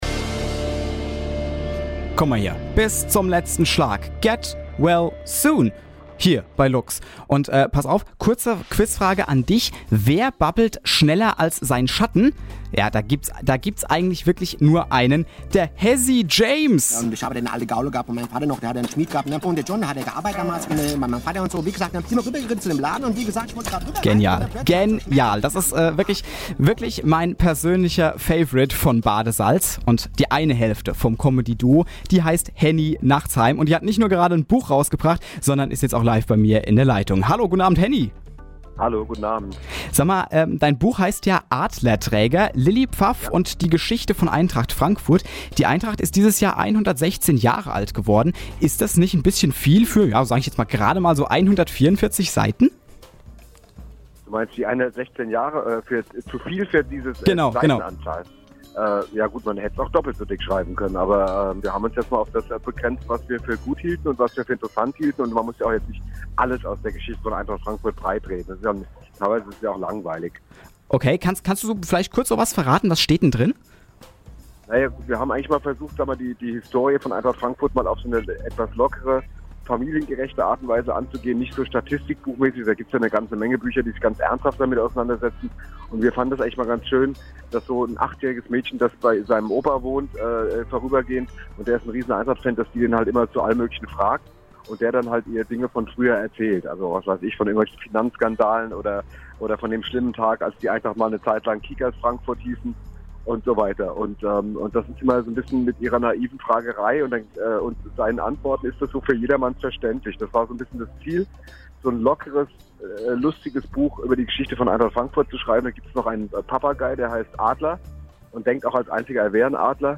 Gestern Abend in der LUX-Sommerwerft